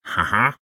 Minecraft Version Minecraft Version 1.21.4 Latest Release | Latest Snapshot 1.21.4 / assets / minecraft / sounds / mob / illusion_illager / idle1.ogg Compare With Compare With Latest Release | Latest Snapshot